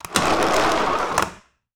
Skateboard Intense Grind.wav